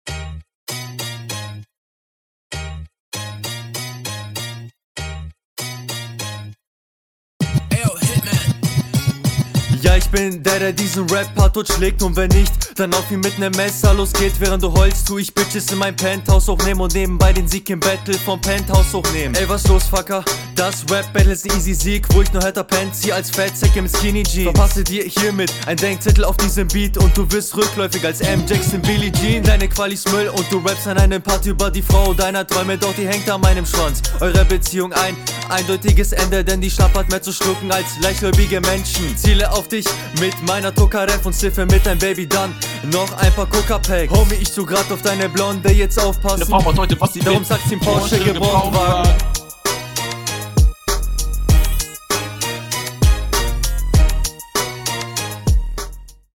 Auch hier fehlt es deinem Flow noch ein bisschen an Übung, klingt noch sehr unsicher.